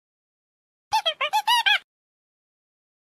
Stars Sound Effects MP3 Download Free - Quick Sounds